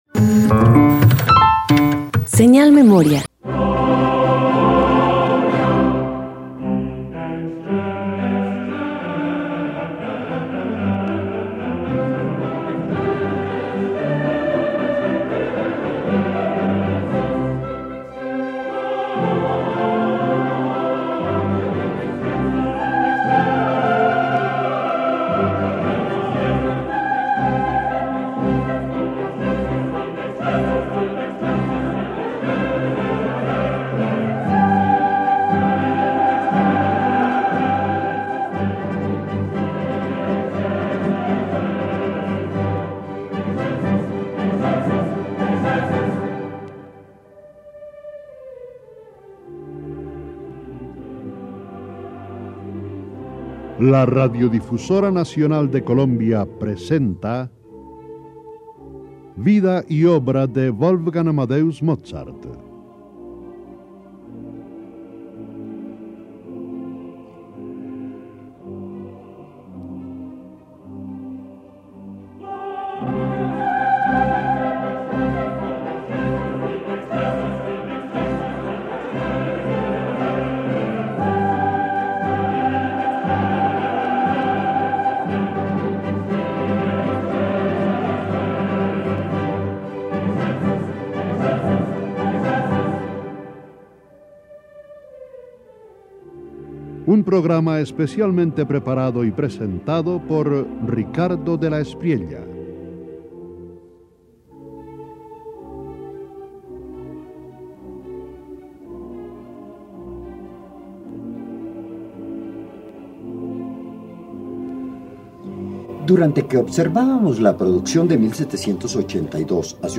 213 Concierto para piano y orquesta con acompañamiento de cuarteto de cuerdas_1.mp3